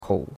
kou3.mp3